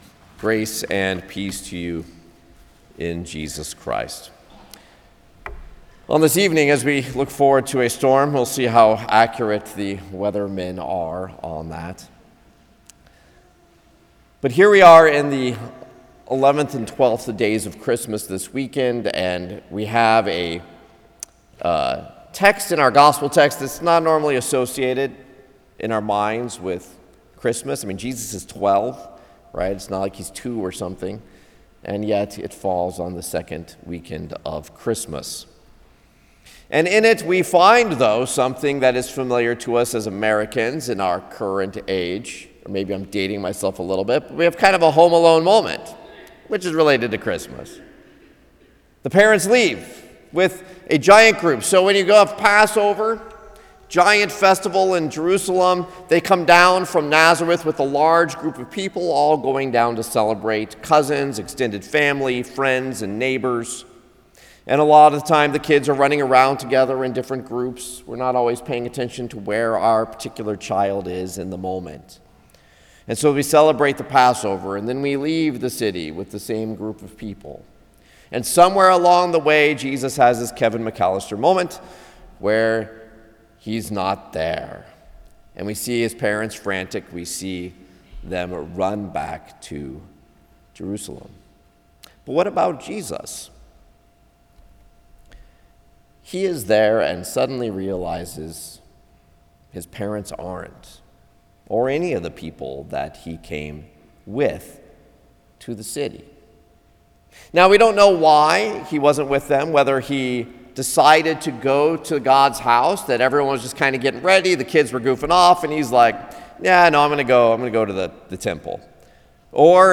Passage: Luke 2:40-2:52 Service Type: Sermon